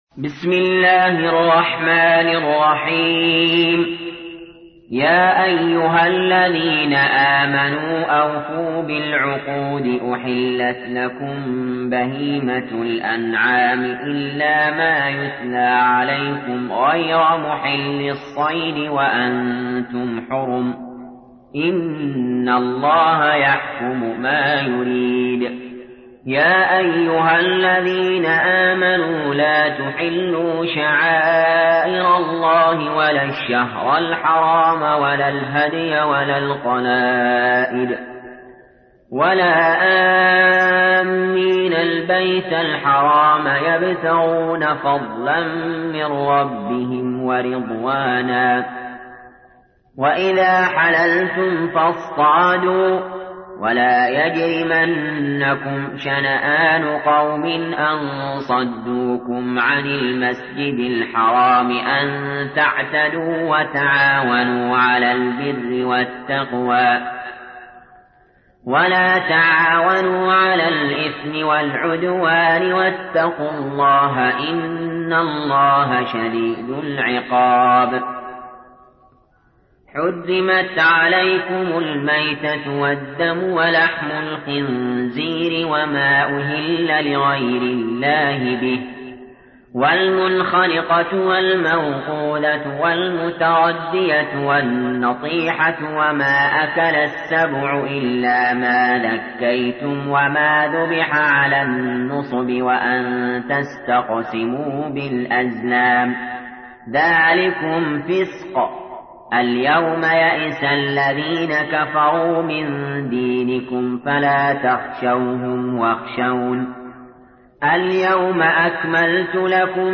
تحميل : 5. سورة المائدة / القارئ علي جابر / القرآن الكريم / موقع يا حسين